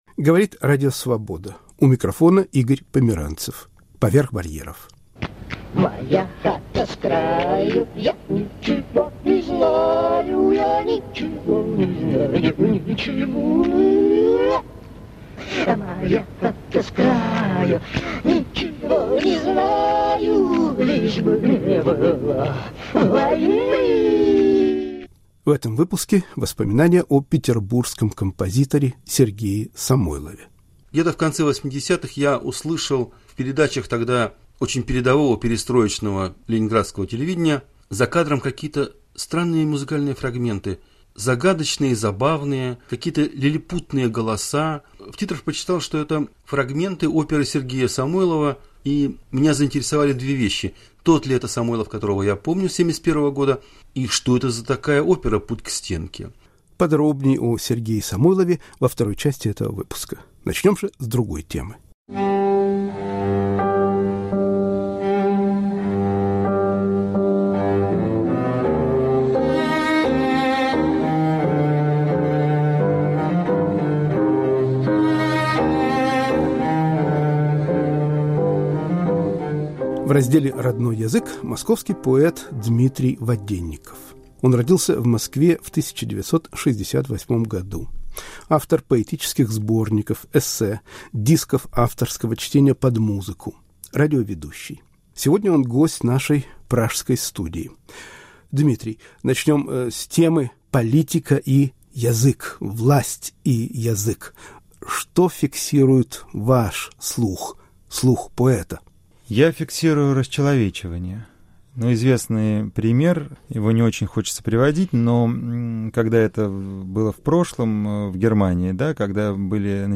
«Родной язык». Поэт Дмитрий Воденников ** «Колдовская сила». В передаче звучат голоса деревенских жителей Псковской области ** «Мои любимые пластинки».